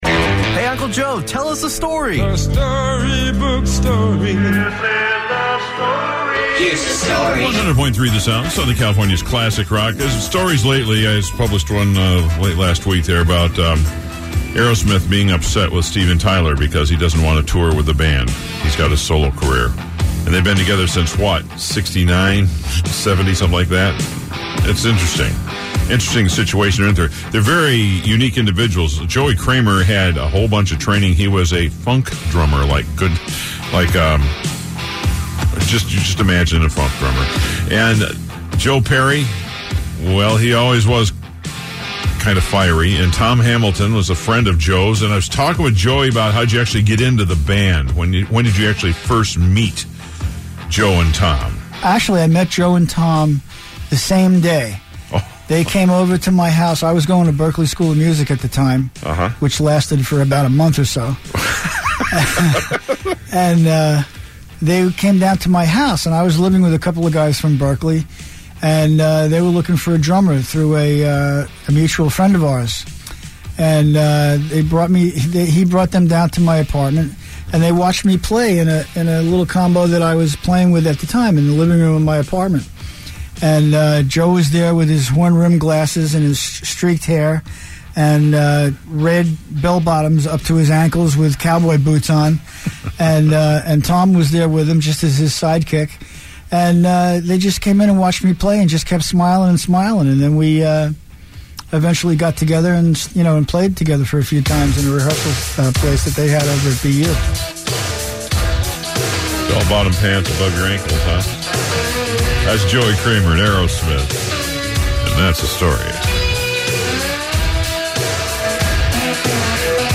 Aerosmith drummer Joey Kramer tells the story about the first time he met Joe Perry and Tom Hamilton.